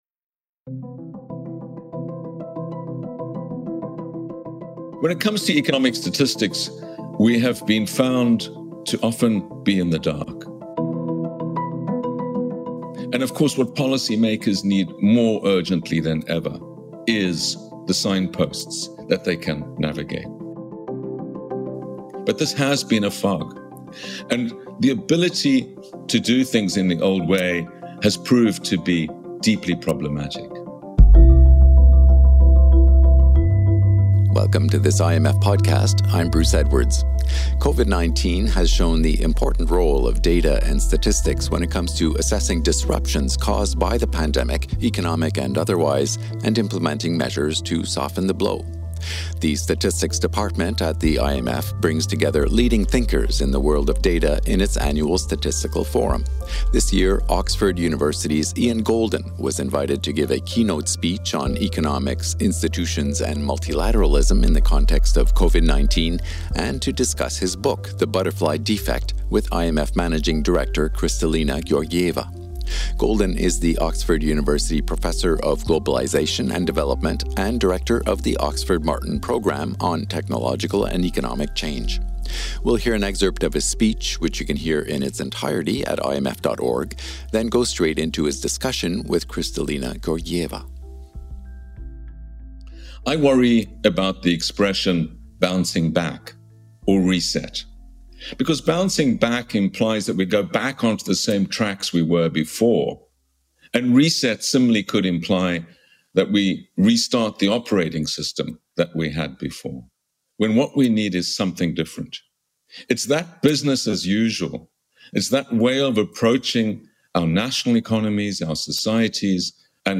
This year, Ian Goldin was invited to give a keynote speech on Economics, Institutions, and Multilateralism in the context of Covid-19, and to discuss his book The Butterfly Defect with IMF Managing Director Kristalina Georgieva.